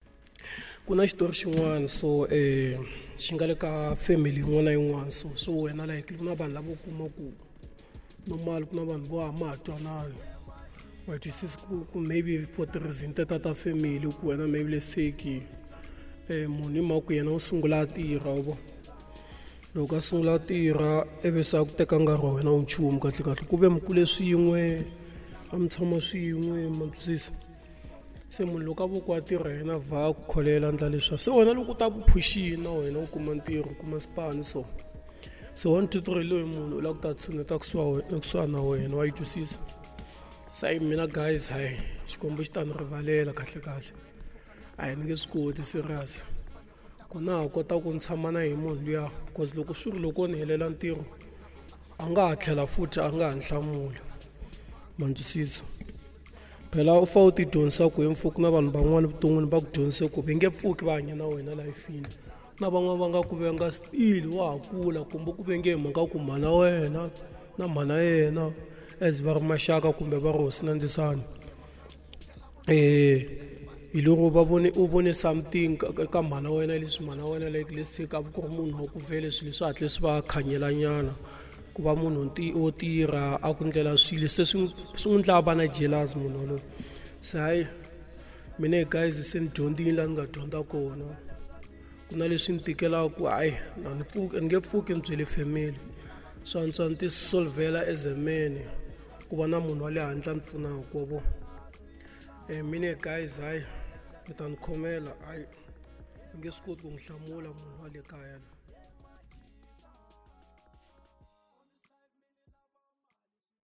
01:53 Genre : Xitsonga Size